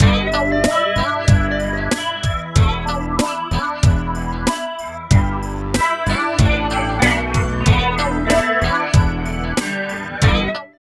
29 LOOP   -L.wav